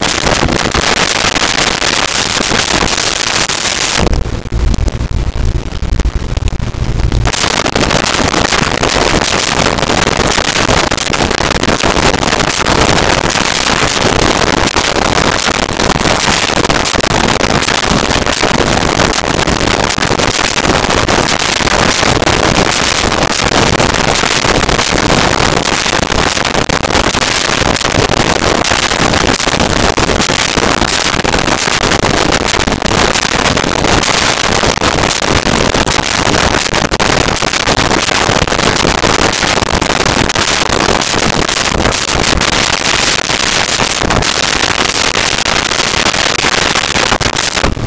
Printer Laser Sequence
Printer Laser Sequence.wav